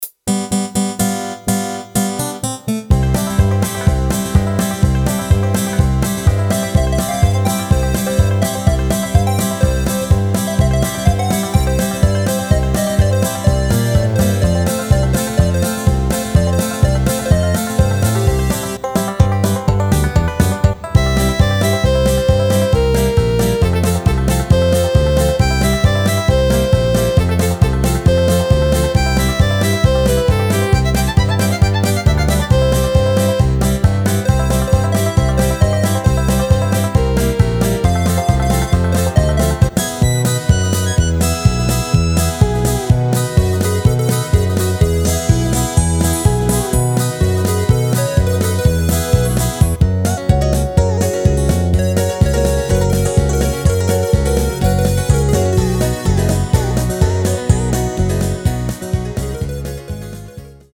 Rubrika: Pop, rock, beat
- směs
Karaoke